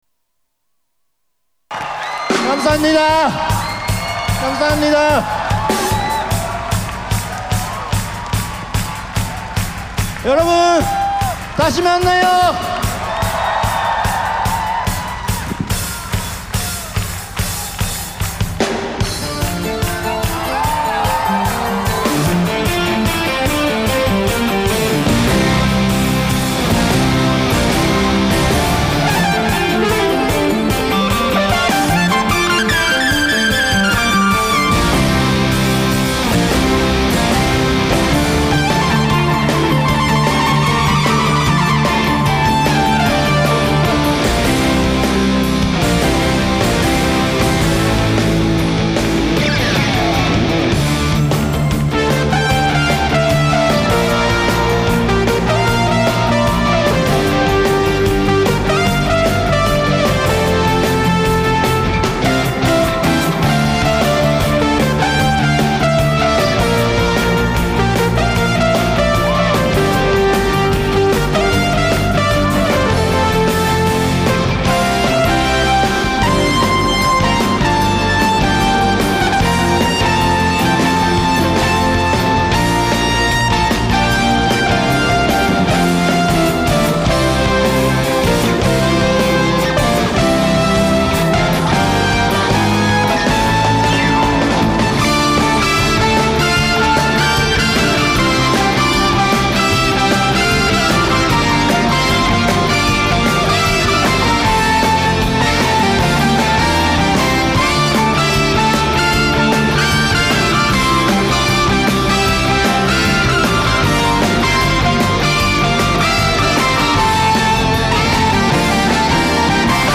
ewi+sax